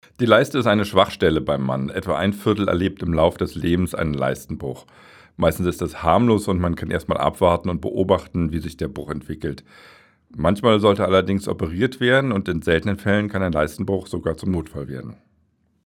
O-Ton